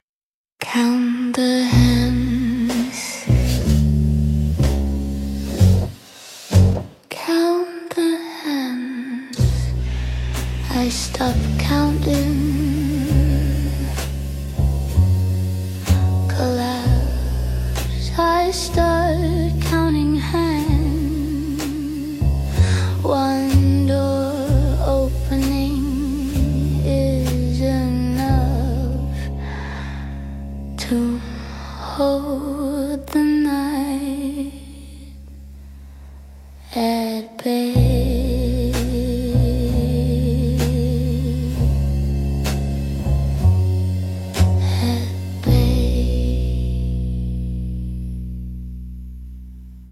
The soundscape and visuals were created with AI.